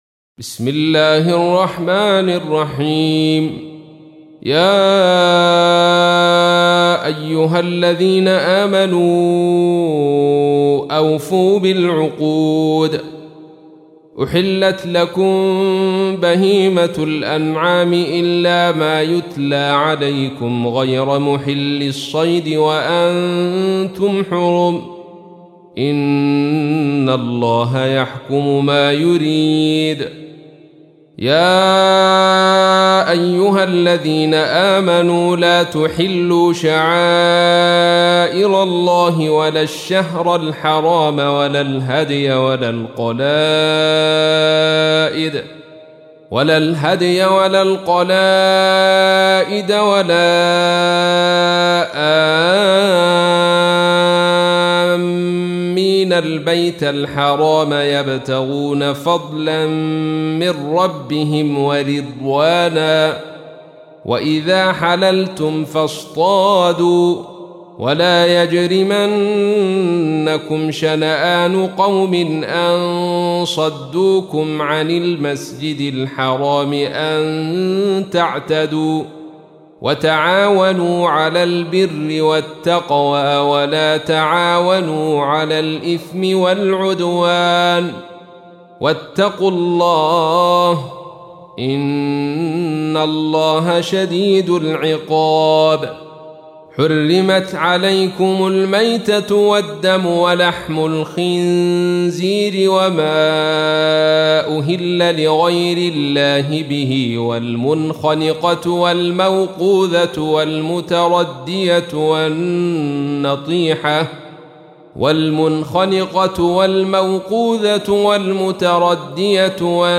تحميل : 5. سورة المائدة / القارئ عبد الرشيد صوفي / القرآن الكريم / موقع يا حسين